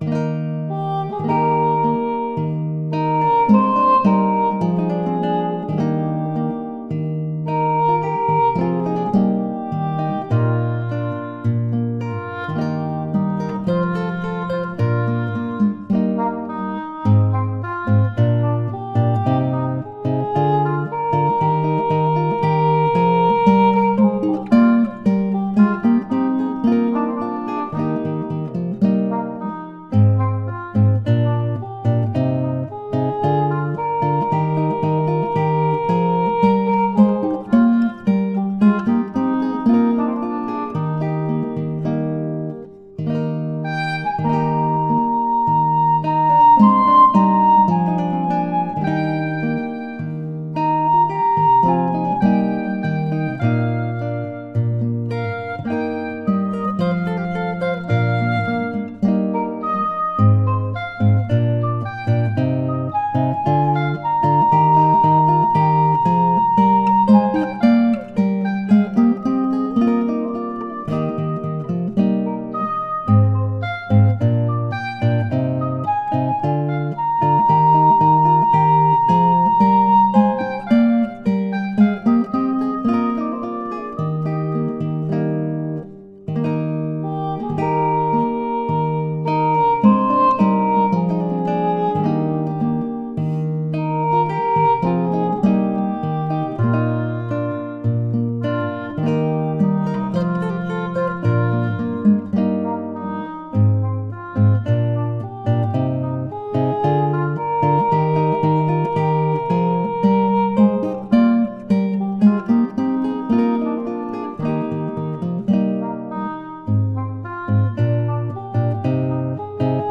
close-miked.
English Horn patch of an Akai EWI USB.
Yeah, I was probably a foot away from the mics.
little treatment in a residential area, but I'll try a little more distance.
I had the feeling it was played on an Akai controller.
your close up, near anechoic method is valid.
If heard via headphones or in a well damped room ( quite rare) it sounds too dry - in an average conditions with considerable reverberation bouncing around instruments sound like they are right in the room with you.